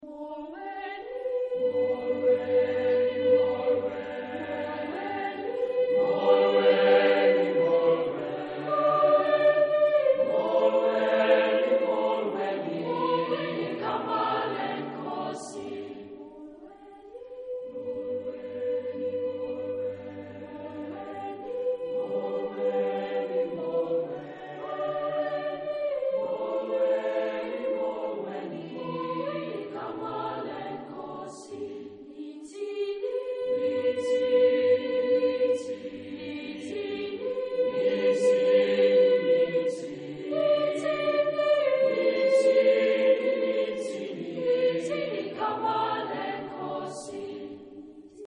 Greeting song
Género/Estilo/Forma: Canción de bienvenida ; Tradicional
Carácter de la pieza : afectuoso
Tipo de formación coral: SATB  (4 voces Coro mixto )
Tonalidad : sol mayor